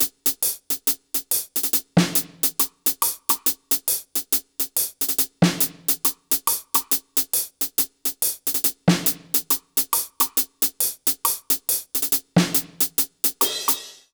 British REGGAE Loop 144BPM (NO KICK).wav